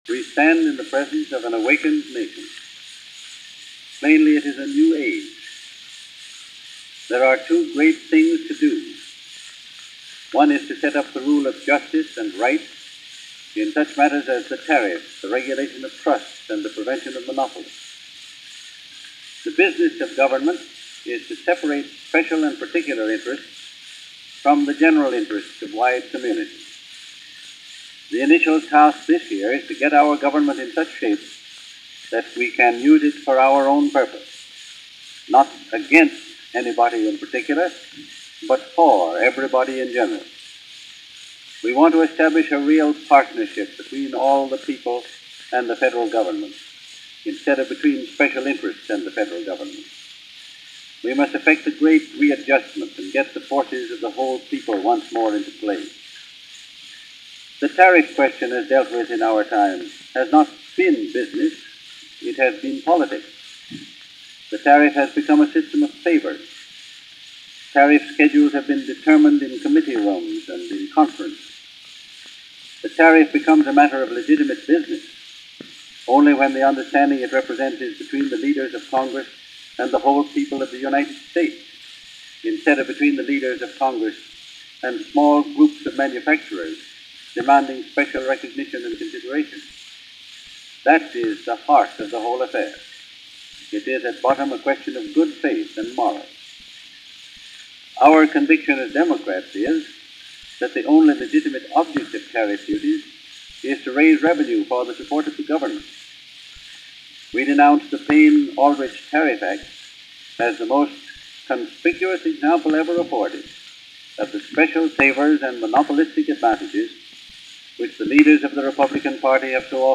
In 1912, during a particularly grueling election campaign, Wilson took advantage of the new technology of the day and released a number of policy addresses on disc.
This address is available via a number of sites – since it was a commercial recording and many thousands were made and sold. This is my original 78 disc, so it may sound a bit different than the others – it’s the same recording.